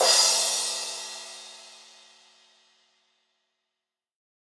ccrsh001-hard.wav